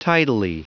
Prononciation du mot tidily en anglais (fichier audio)
Prononciation du mot : tidily